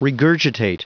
Prononciation du mot regurgitate en anglais (fichier audio)
regurgitate.wav